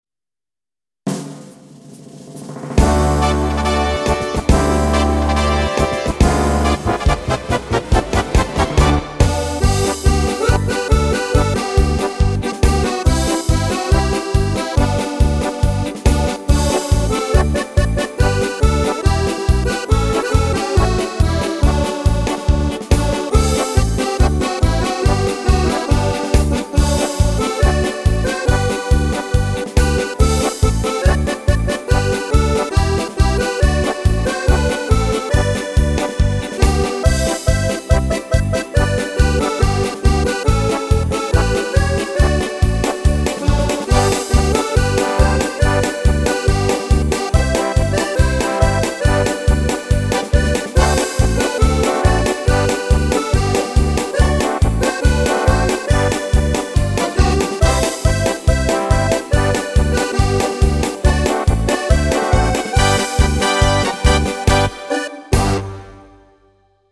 Gesellschaftslied
Instrumental: „Wir sind die Ritzerfelder Jonge“